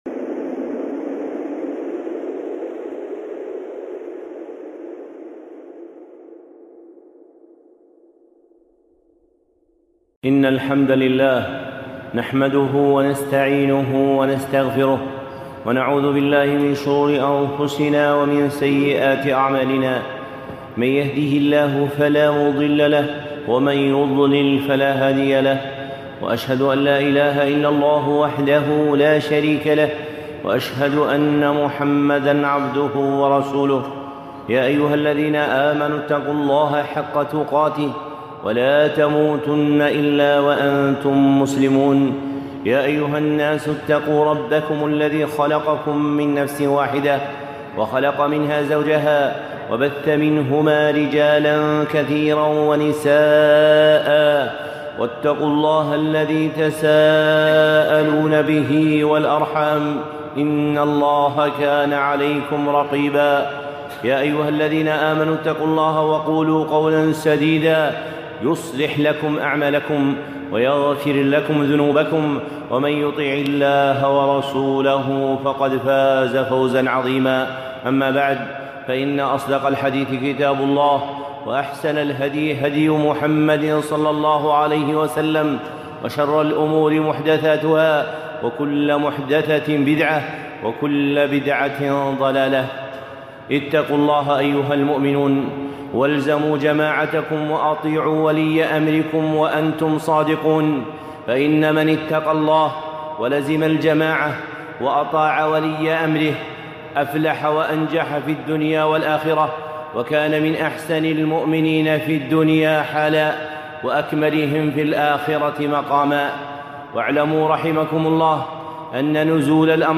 خطبة (أذكار الأمطار)